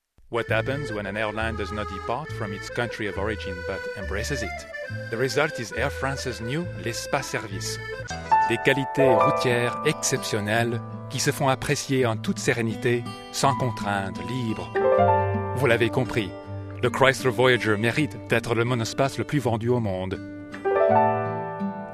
englisch (us)
mid-atlantic
Sprechprobe: Werbung (Muttersprache):